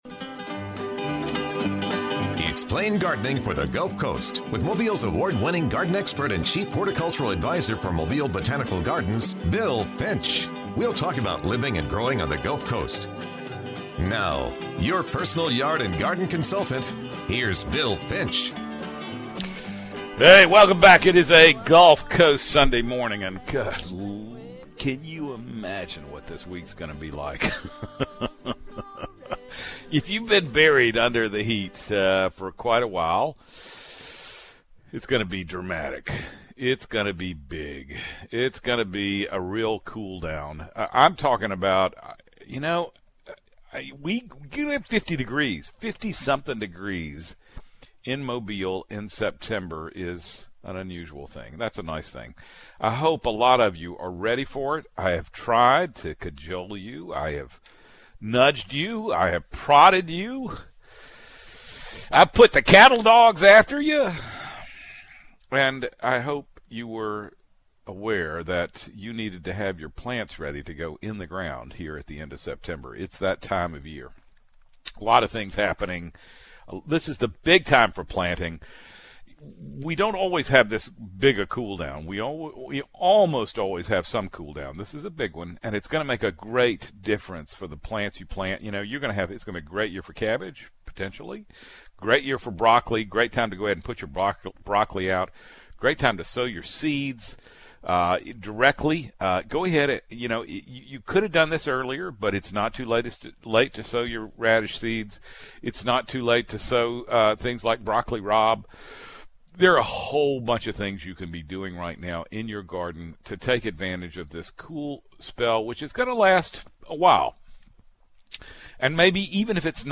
weekly gulf coast garden show